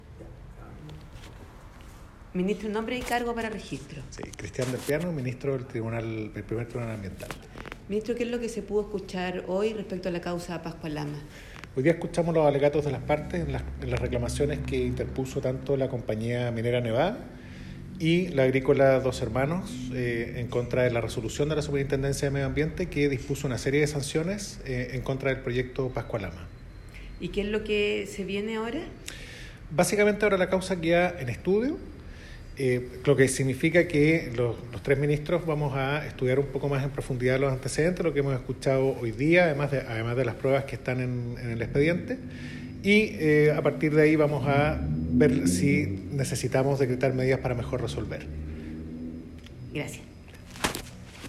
Al finalizar la audiencia el Ministro presidente de sala, Cristián Delpiano Lira explicó que la causa quedó en estudio, “lo que significa que los tres ministros vamos a estudiar un poco más en profundidad los antecedentes, lo que hemos escuchado hoy además de las pruebas que están en el expediente y a partir de ahí vamos a ver si se decretan medidas para mejor resolver”.